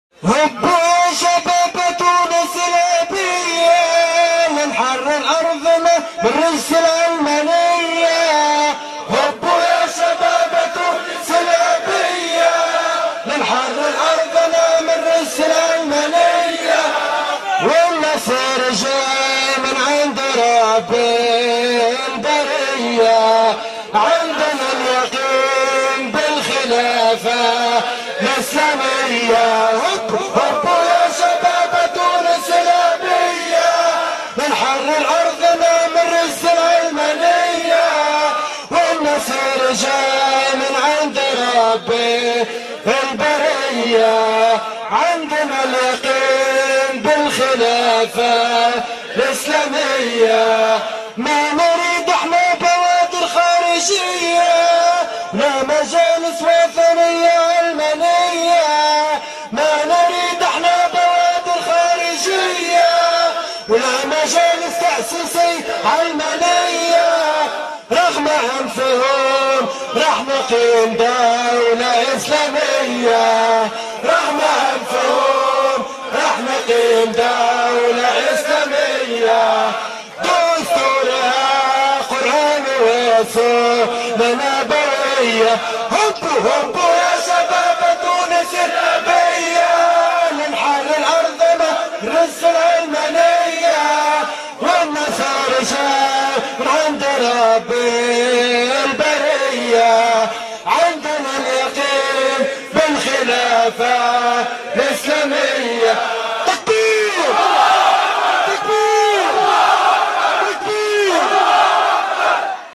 أنشودة